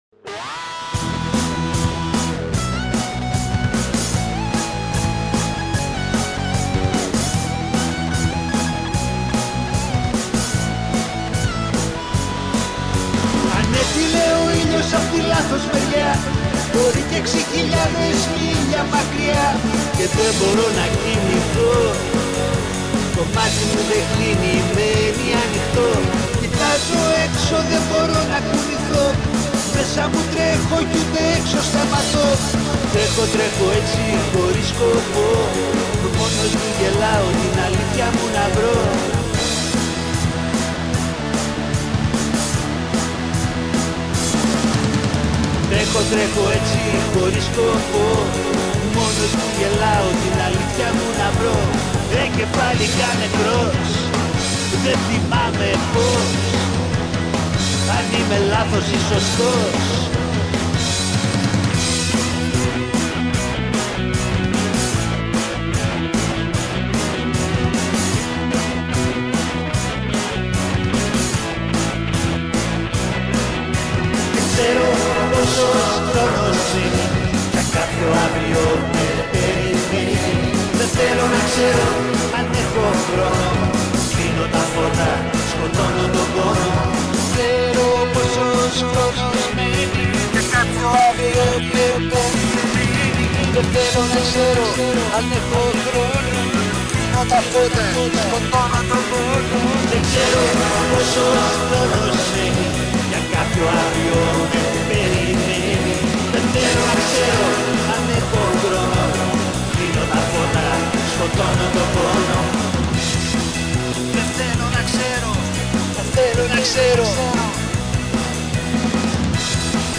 Σε μια μεγάλη συνέντευξη μας διηγείται το ξεκίνημα του από το 1970 ακόμα, την μετοίκηση του στην Νορβηγία λίγο αργότερα και την απαρχή της προσωπικής του δουλειάς μετά τα συγκροτήματα.